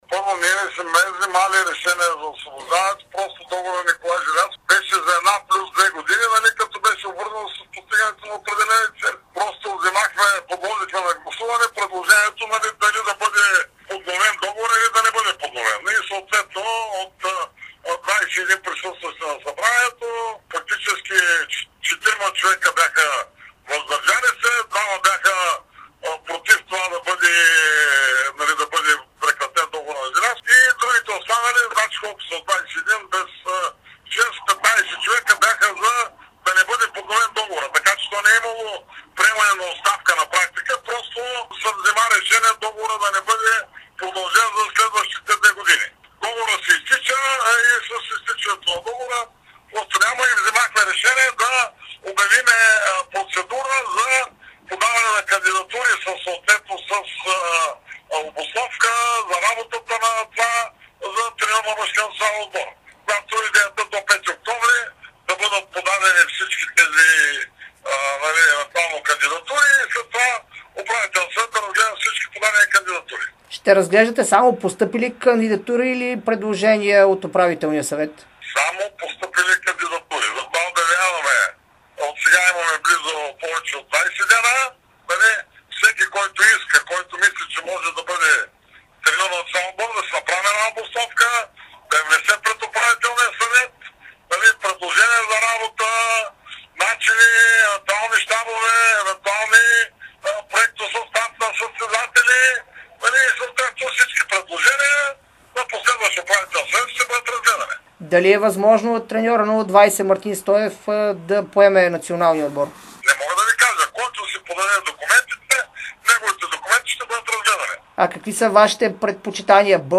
Президентът на Българската федерация по волейбол Любо Ганев даде специално интервю за Дарик радио и dsport.